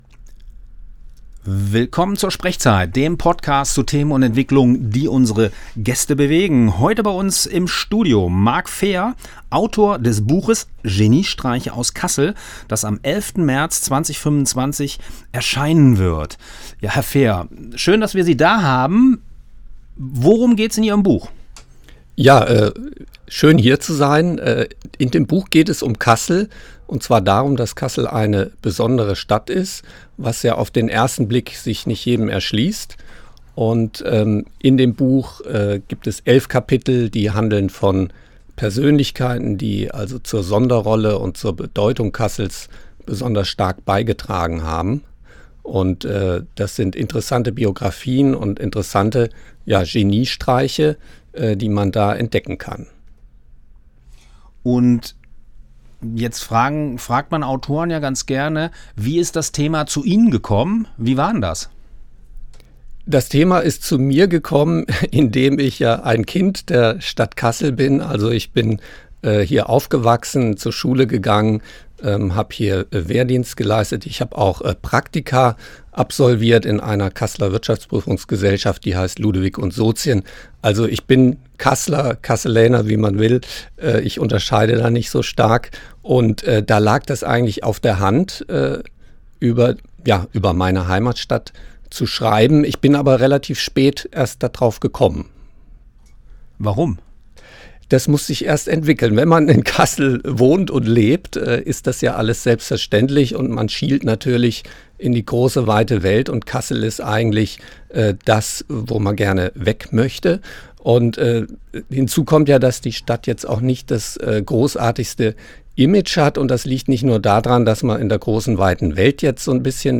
Dieser SprechZeit-Podcast wurde am 24.2.2025 beim Freien Radio Kassel aufgezeichnet.
Interview